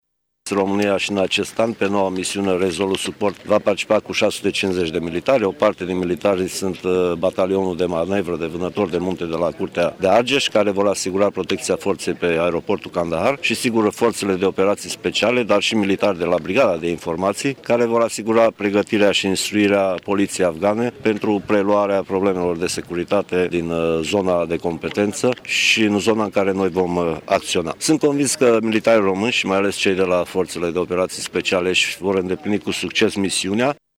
Ceremonia de plecare a avut loc, în această dimineaţă, la sediul Unităţii Militare 01010 din Tîrgu Mureş în prezenţa Ministrului Apărării Naţionale, Mircea Duşa.
Ministrul Apărării Naţionale, Mircea Duşa, a spus că România participă în prezent cu 650 de militari la operaţiunile din Afganistan: